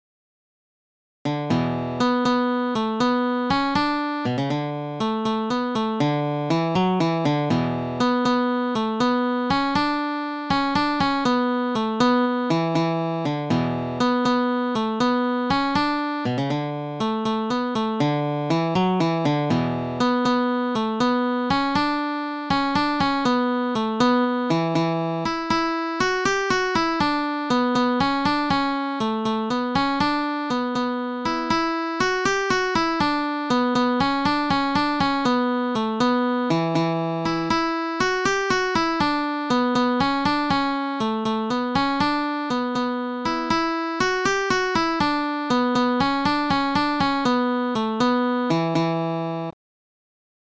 The Road to Lisdoonvarna | Guitar
road_to_lisdoonvarna_guitar.mp3